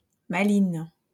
Mechelen (Dutch pronunciation: [ˈmɛxələ(n)] ; French: Malines [malin]